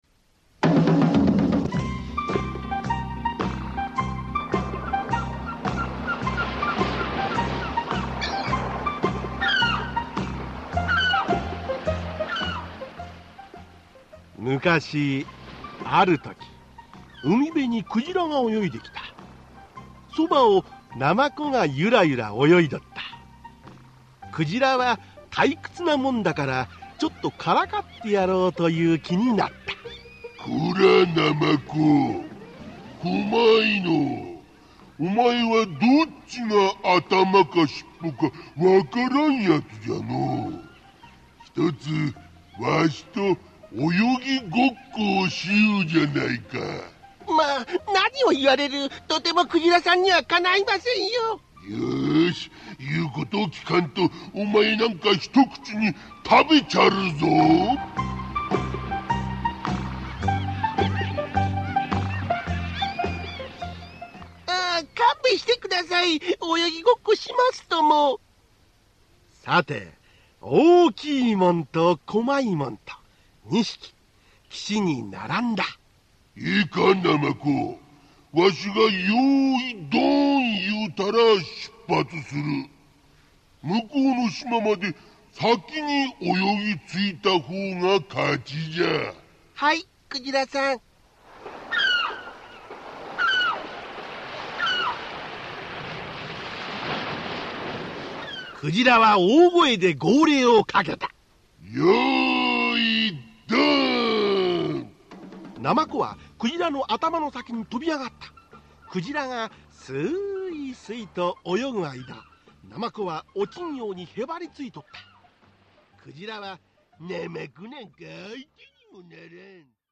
[オーディオブック] どうぶつたちのきょうそう